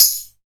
Shaken Tamb 04.wav